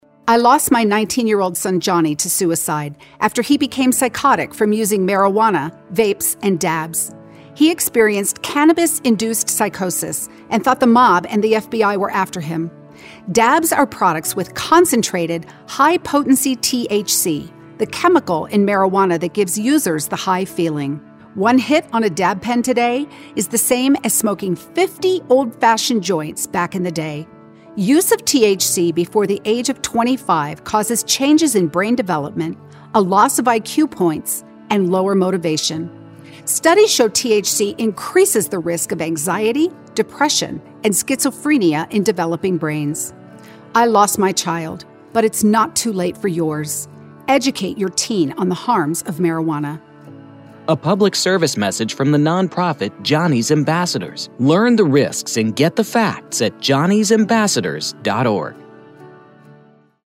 Television and Radio PSA Ads Free For You to Use!
RADIO